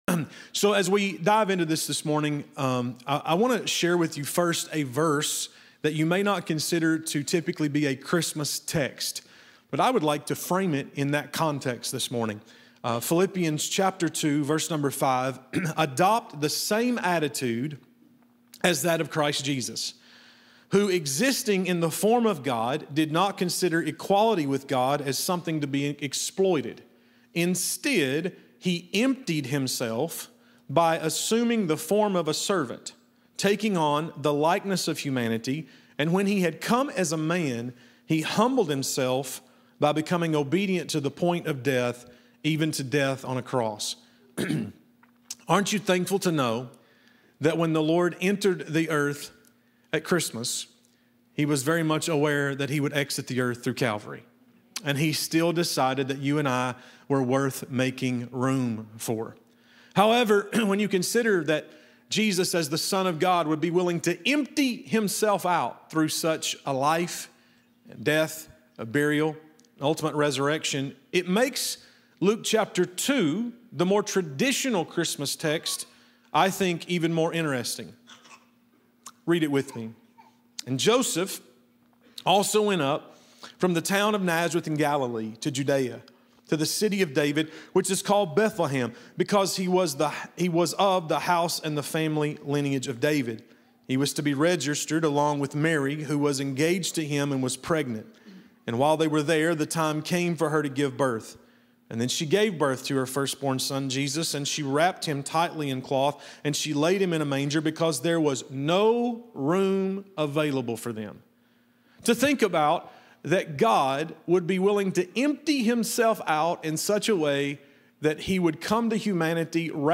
In this Christmas message, we explore how Jesus intentionally made room—for God first and for people second—and how barriers can quietly crowd out love, trust, and connection. Discover how humility, prayer, and intentional space can restore both your relationship with God and your relationships with others.